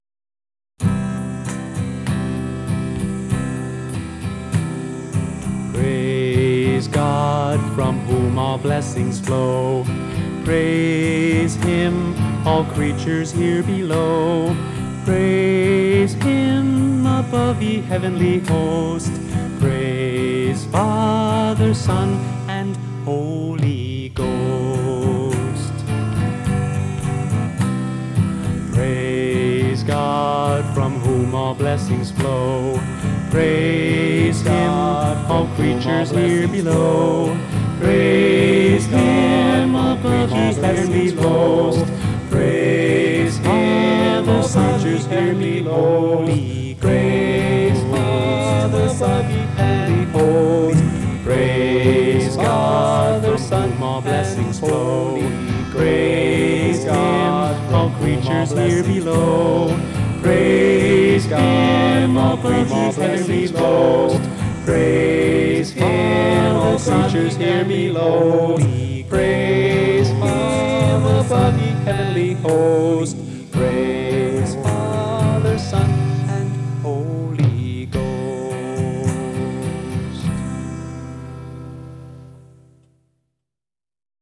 [Karaoke Video - with vocal]